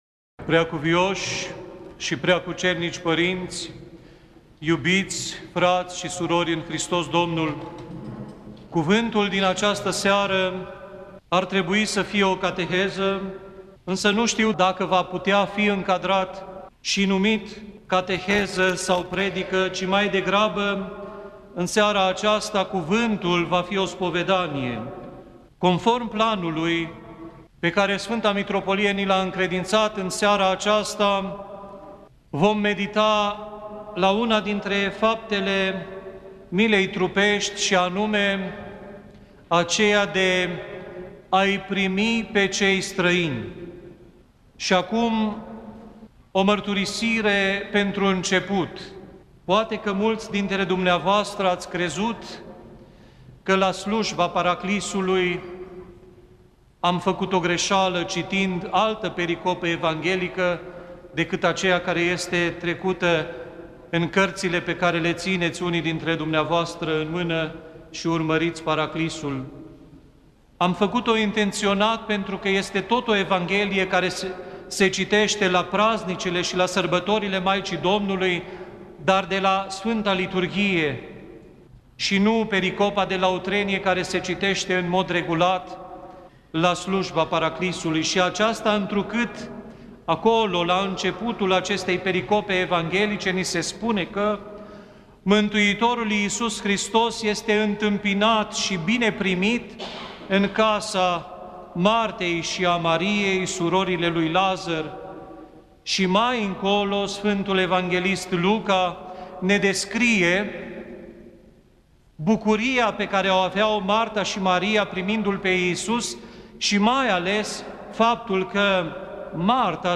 cateheză